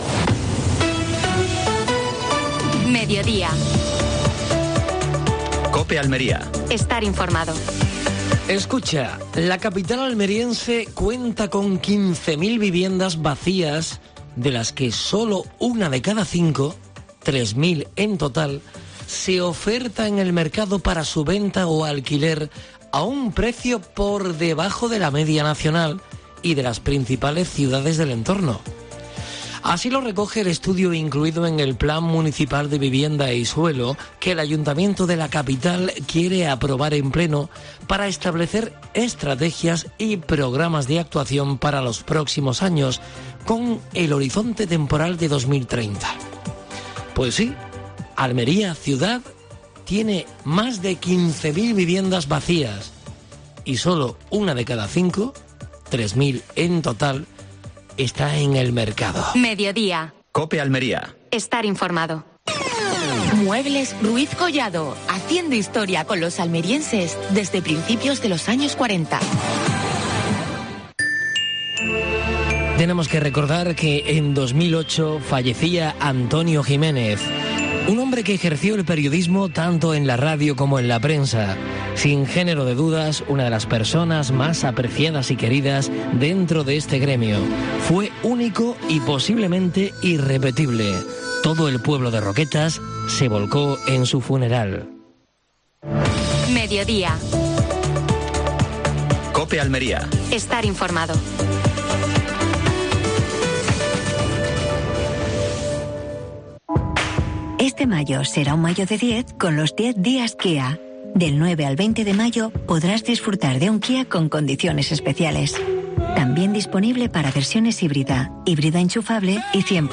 Actualidad en Almería. Última hora deportiva. Programación especial de COPE Almería desde Tecnobioplant.